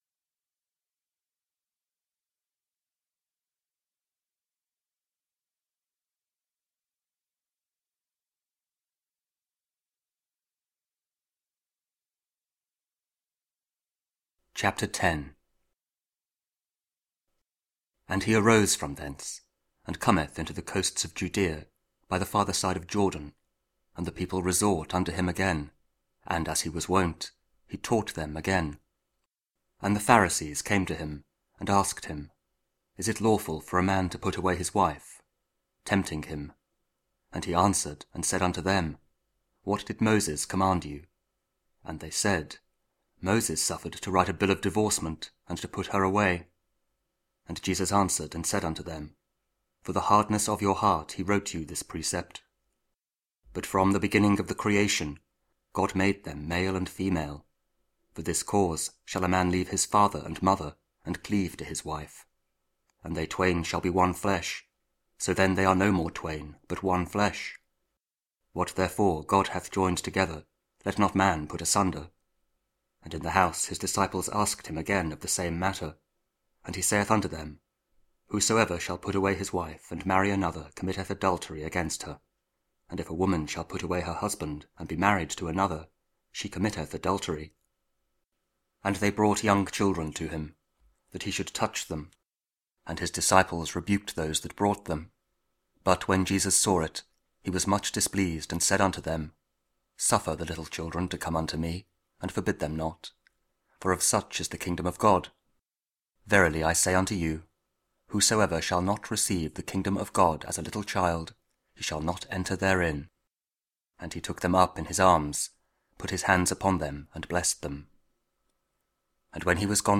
Mark 10: 35-45 – 29th Sunday Year B (Audio Bible KJV, Spoken Word)